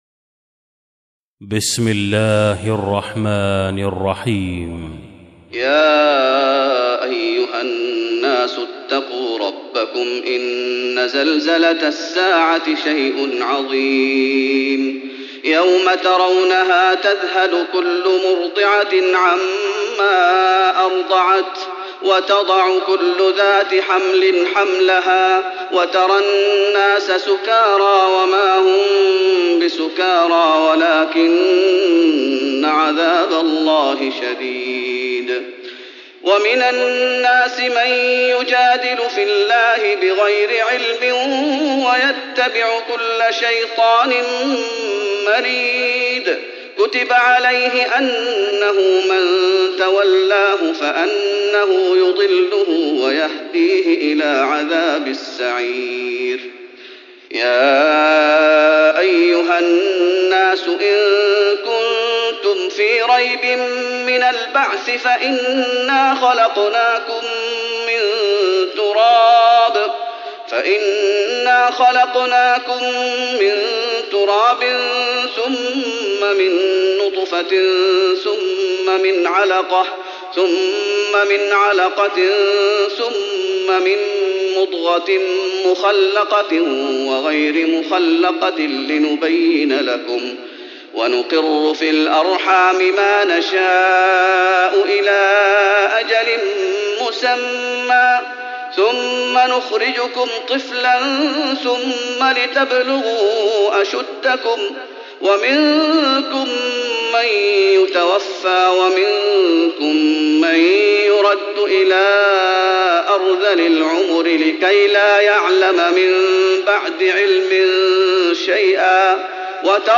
تراويح رمضان 1415هـ سورة الحج (1-37) Taraweeh Ramadan 1415H from Surah Al-Hajj > تراويح الشيخ محمد أيوب بالنبوي 1415 🕌 > التراويح - تلاوات الحرمين